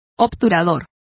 Complete with pronunciation of the translation of chokers.